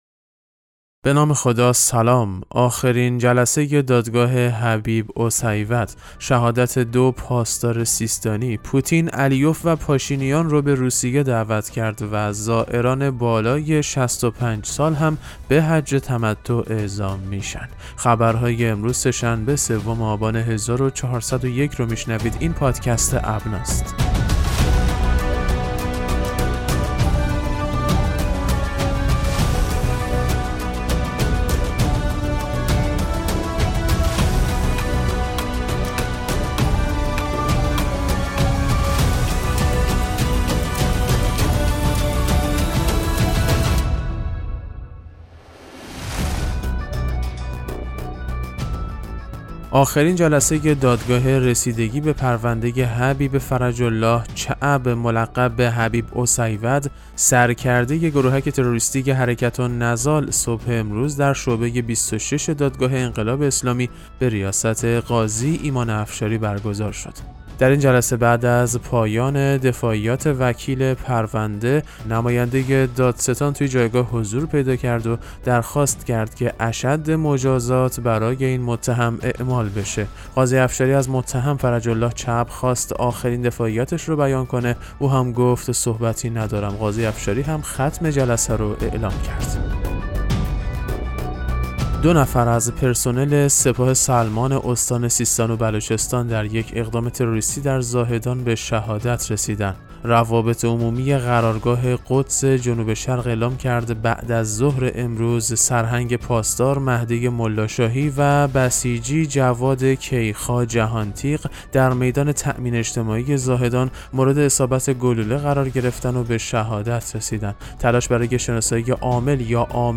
پادکست مهم‌ترین اخبار ابنا فارسی ــ سوم آبان 1401